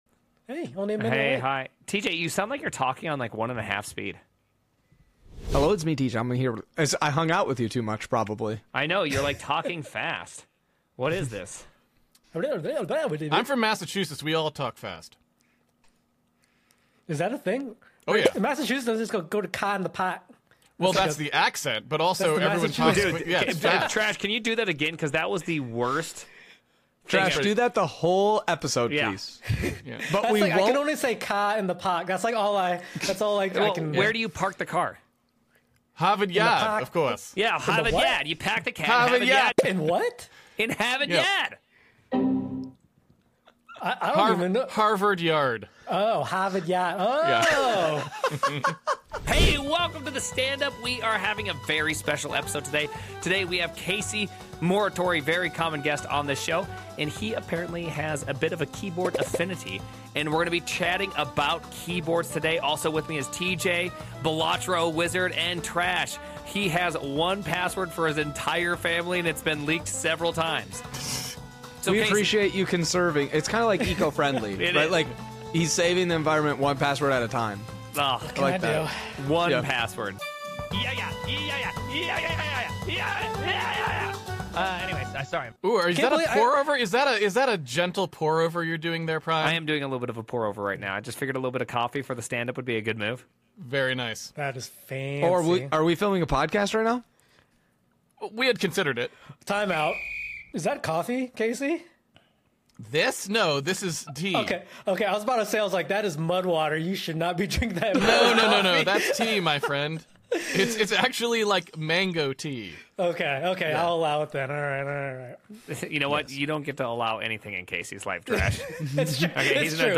ASMR & Key Sounds Showcase